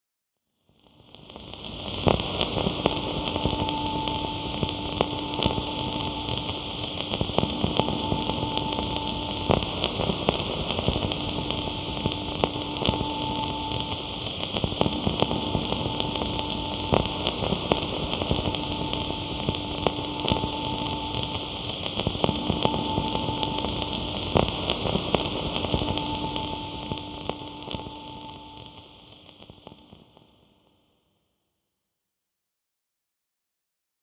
Download Hot Rod sound effect for free.